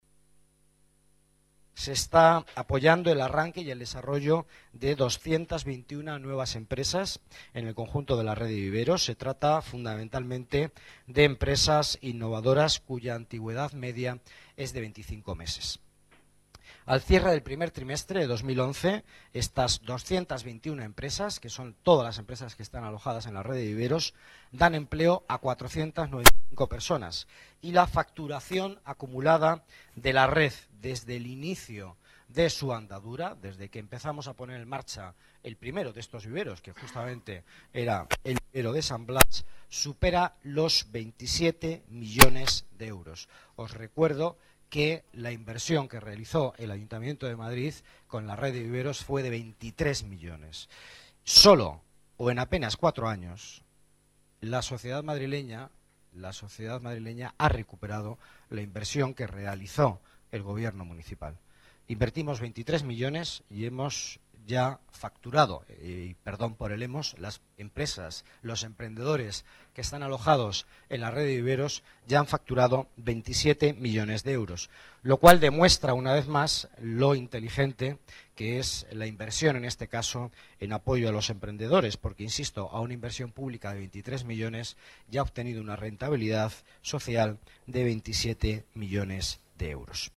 Nueva ventana:Declaraciones delegado Economía y Empleo, Miguel Ángel Villanueva: éxito Madrid Emprende, Memoria 2010